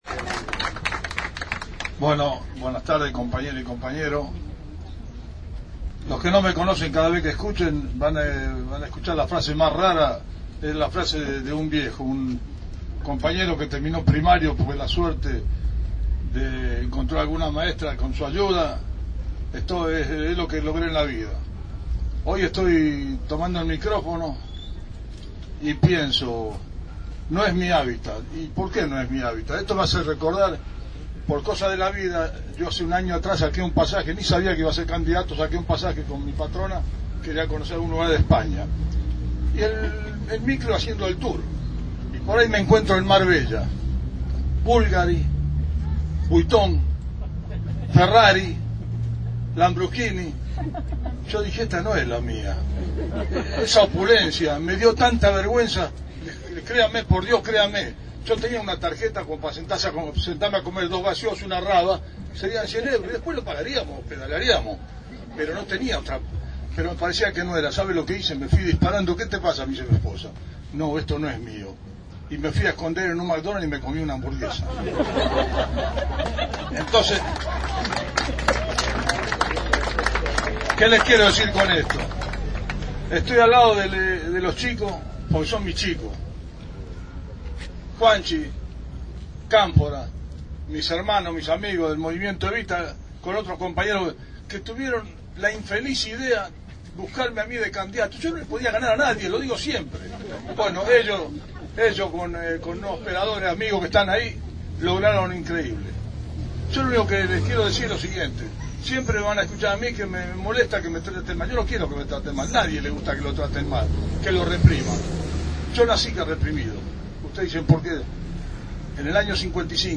En la esquina del pasaje Coronel Rico y avenida Suárez se descubrió una placa en su memoria.
Aquí algunos de los discursos que se escucharon.